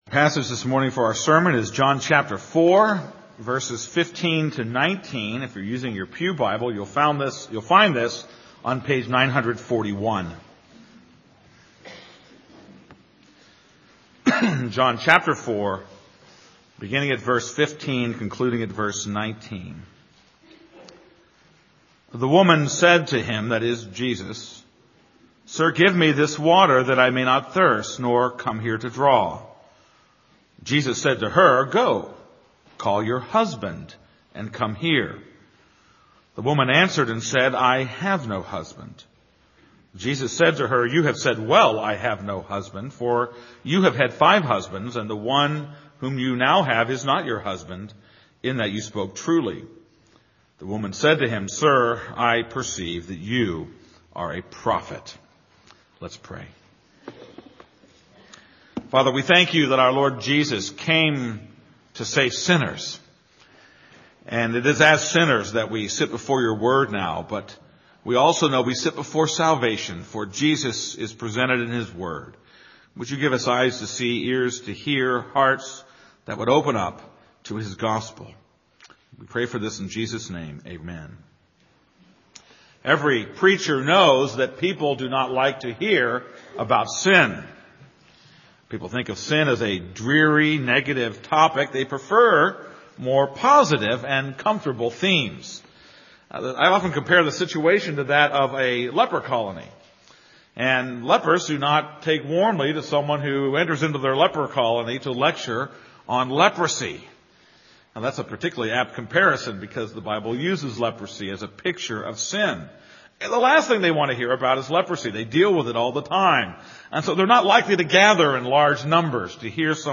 This is a sermon on John 4:15-19.